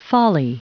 Prononciation du mot folly en anglais (fichier audio)
Prononciation du mot : folly